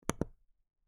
button-click.mp3